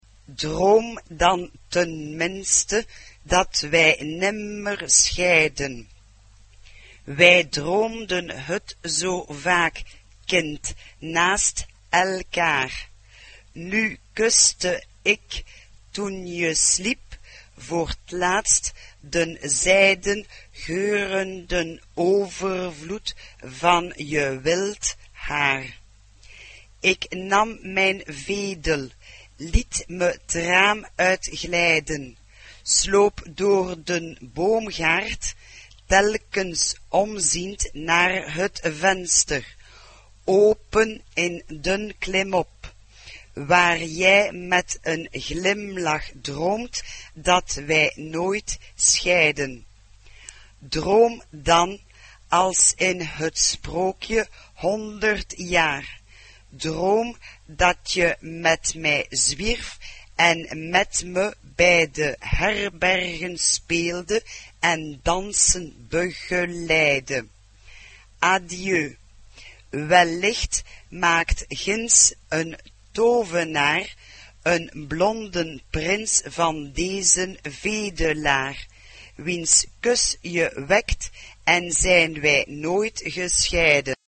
SATB (4 voix mixtes) ; Partition complète.
Chœur.